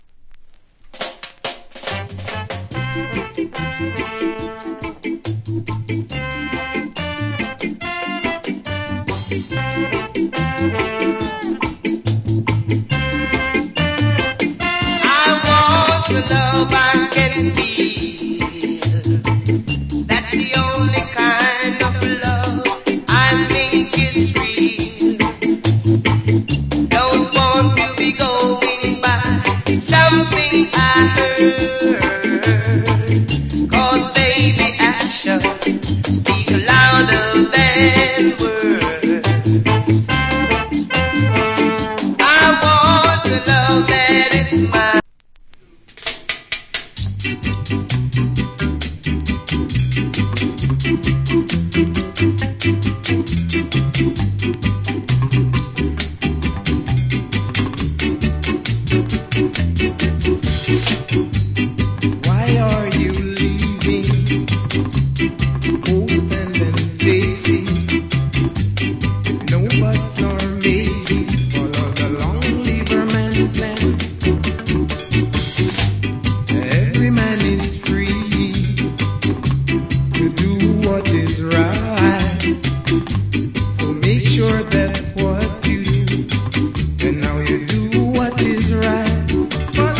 Great Early Reggae Vocal.